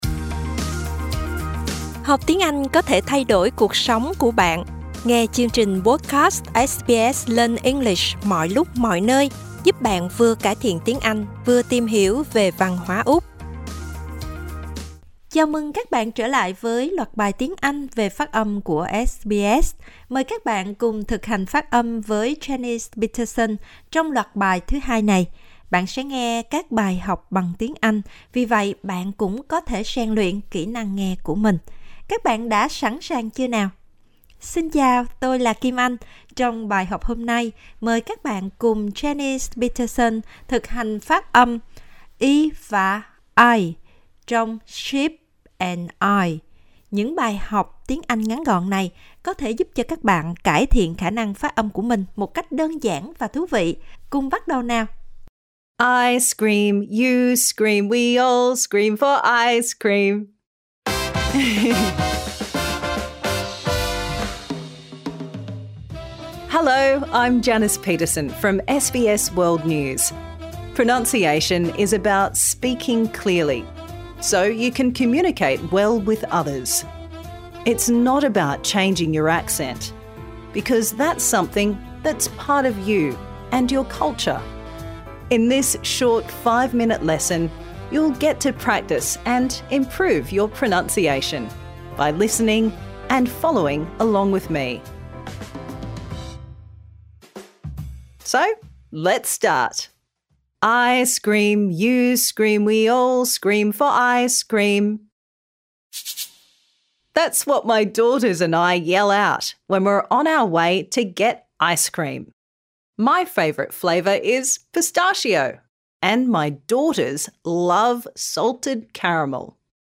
Improve your pronunciation | Season 2